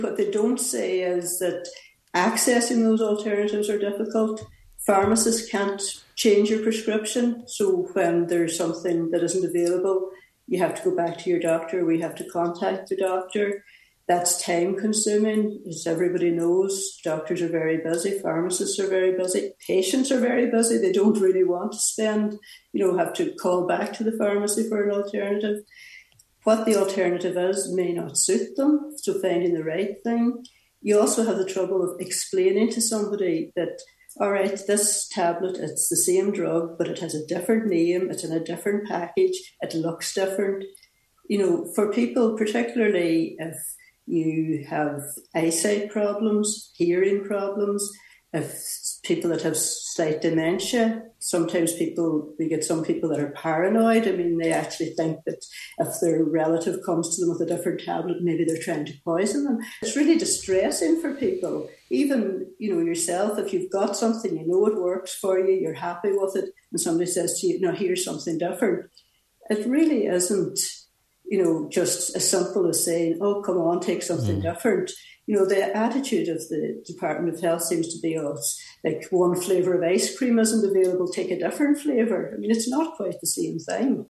speaking on this morning’s Nine til Noon Show however